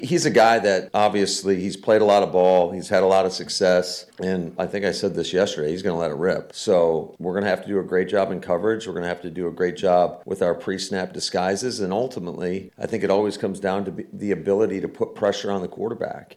(LEARFIELD) – Green Bay Packers coach Matt LaFleur covered a lot of topics when he spoke with the media on Thursday.
LaFleur talked about facing Winston.